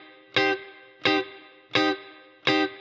DD_TeleChop_85-Cmin.wav